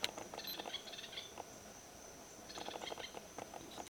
Pichororé (Synallaxis ruficapilla)
Nome em Inglês: Rufous-capped Spinetail
Fase da vida: Adulto
Localidade ou área protegida: Bio Reserva Karadya
Condição: Selvagem
Certeza: Gravado Vocal